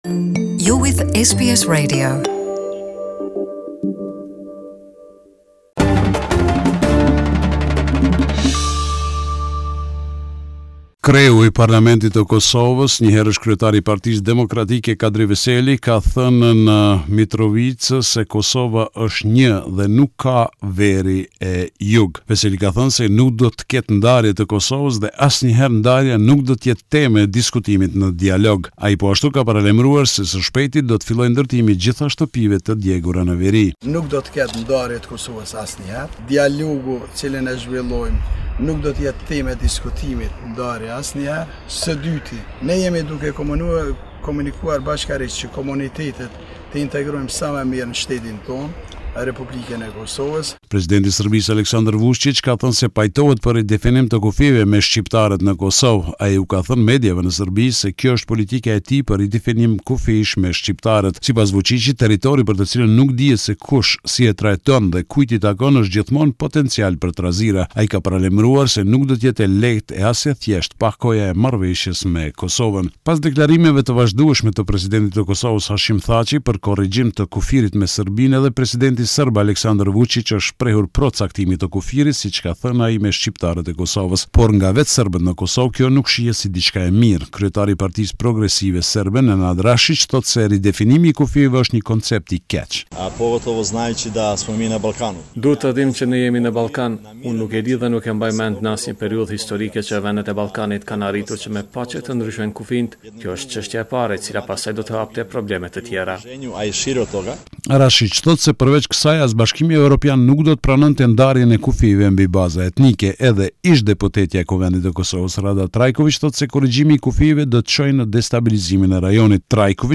This is a report summarising the latest developments in news and current affairs in Kosovo